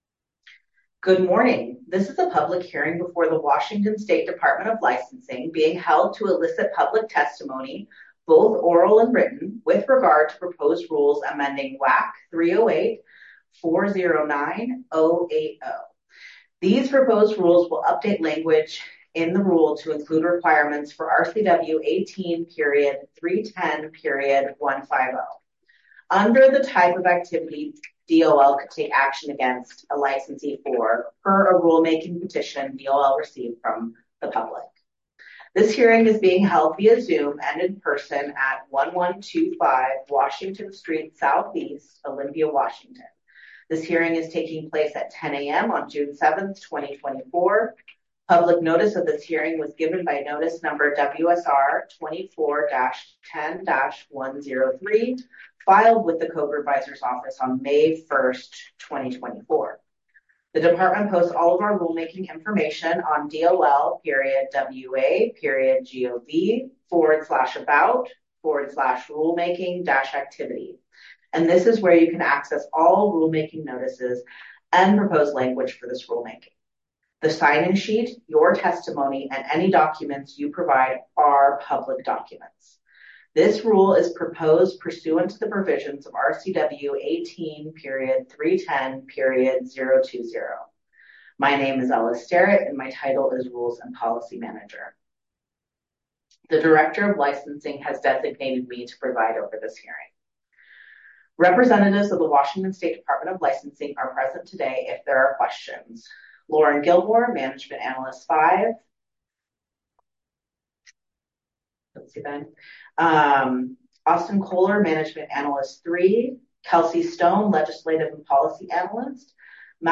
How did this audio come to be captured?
We held a public hearing for this rulemaking activity on June 7, 2024. 10 a.m.